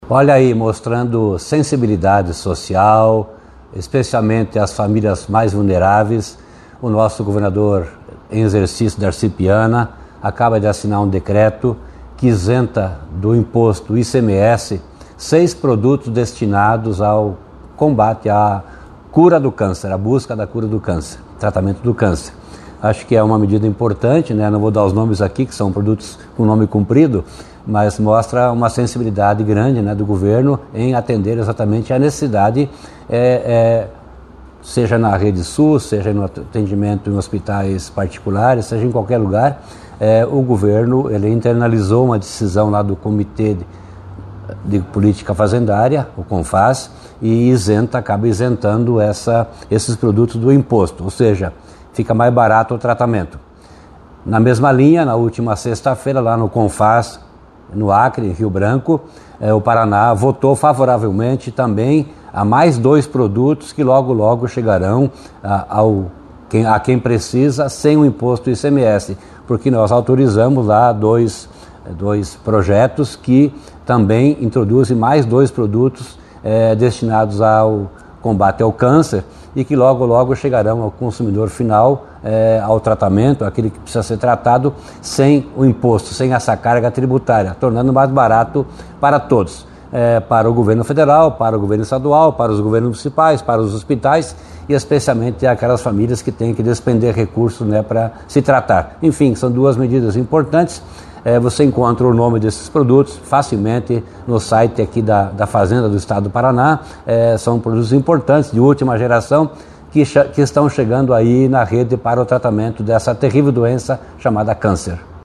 Sonora do secretário Estadual da Fazenda, Norberto Ortigara, sobre a isenção de ICMS para seis medicamentos contra o câncer